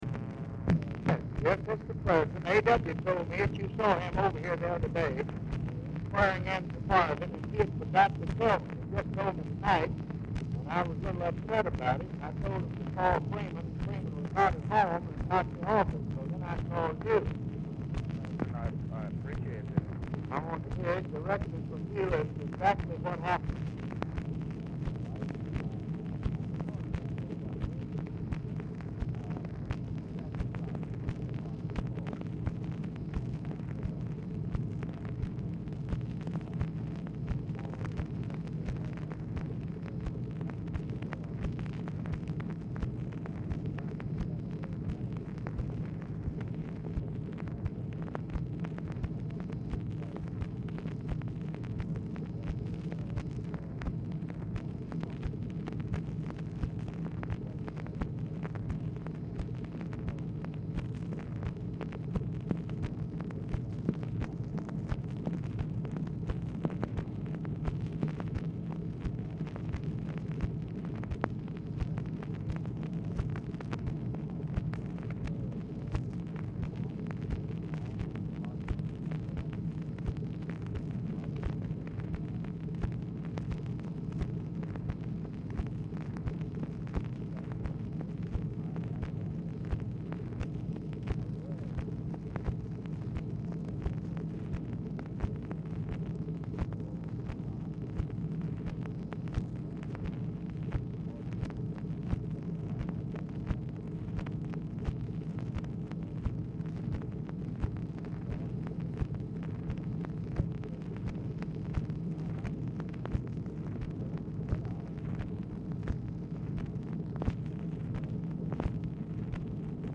Telephone conversation
VERY POOR SOUND QUALITY; CONVERSATION IS ALMOST COMPLETELY INAUDIBLE;
Format Dictation belt